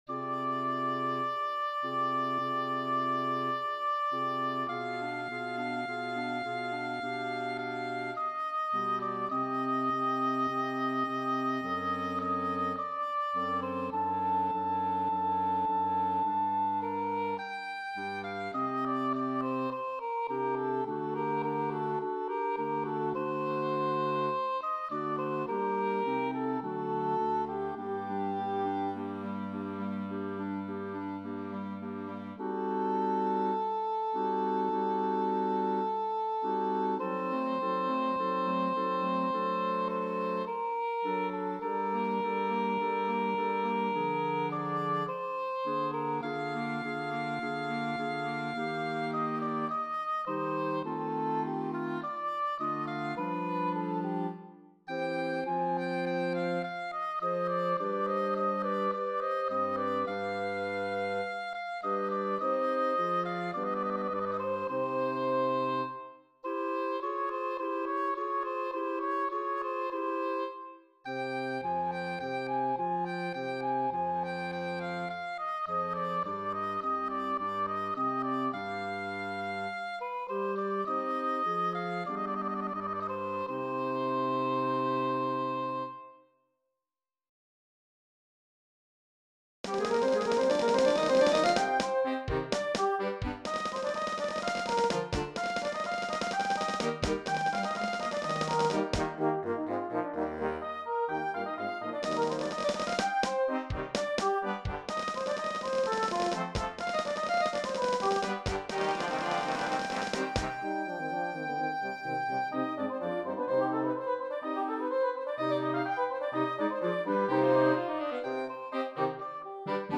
Voicing: Oboe and Concert Band